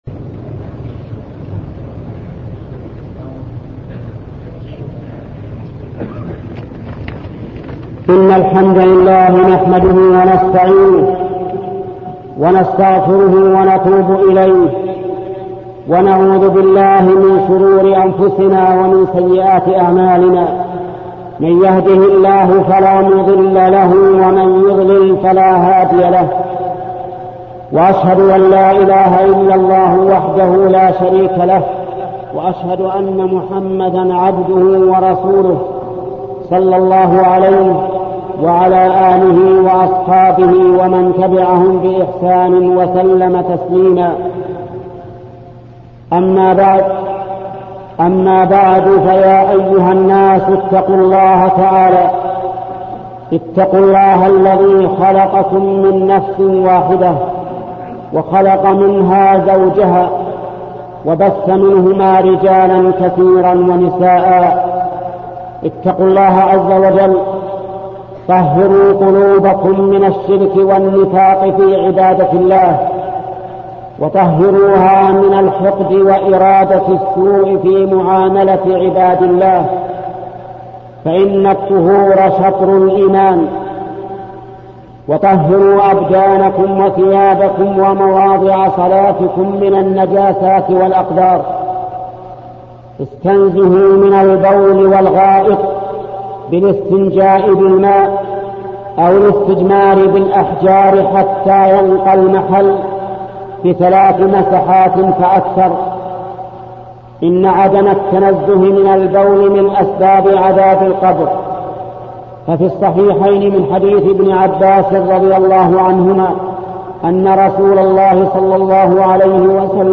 خطبة لشيخ محمد بن صالح العثيمين بعنوان أمور فقهية حول المسح على الخفين